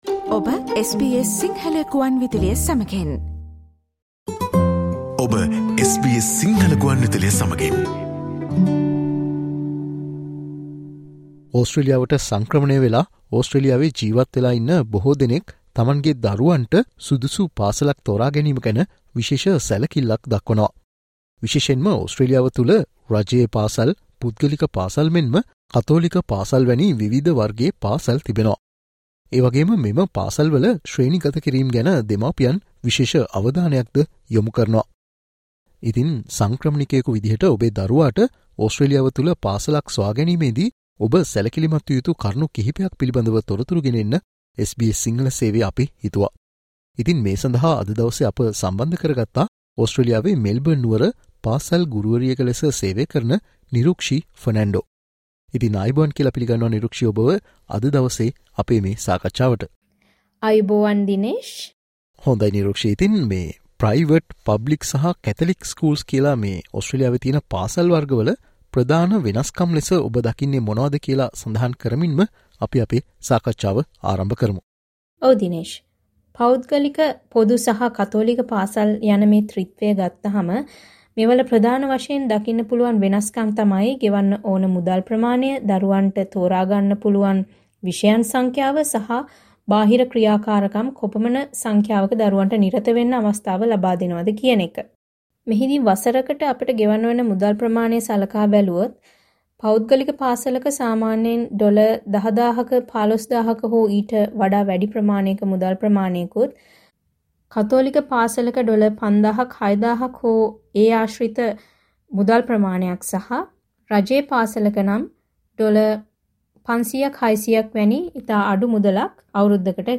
ඔස්ට්‍රේලියාවට සංක්‍රමණය වූ ඔබ විසින් ඔබේ දරුවාට සුදුසු පාසලක් තෝරා ගැනීමේදී සැලකිලිමත් විය යුතු කරුණු පිළිබඳව SBS සිංහල සේවය සිදුකල සාකච්ඡාවට සවන්දෙන්න